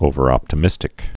(ōvər-ŏptə-mĭstĭk)